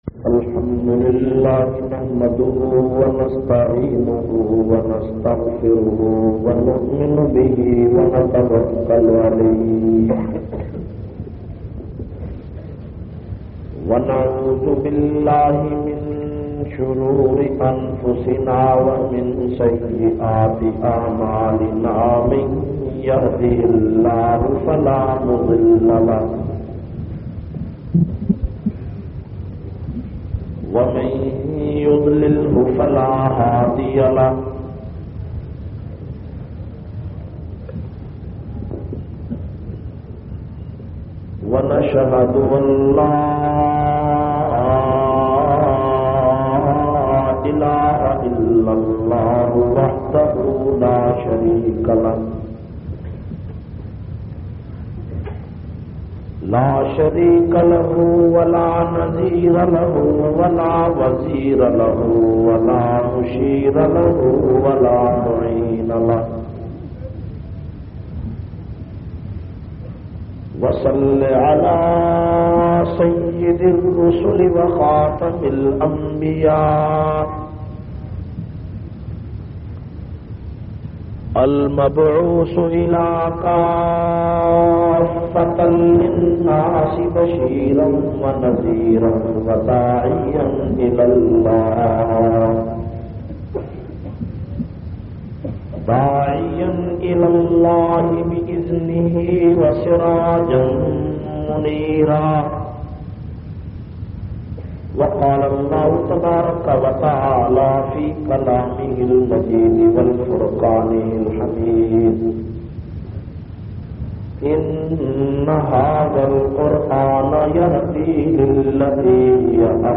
644- Hifazat e Quran Conference-Jamia Taleem Ul Quran,Raja Bazar, Rawalpindi.mp3